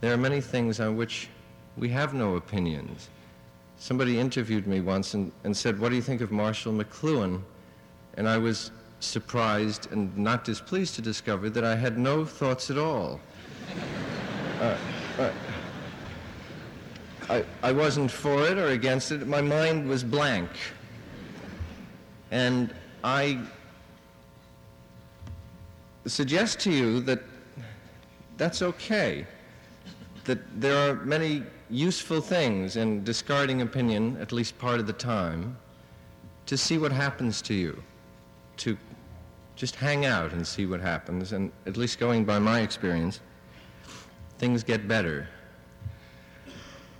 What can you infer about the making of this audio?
Listen to his 1972 Rochester commencement address.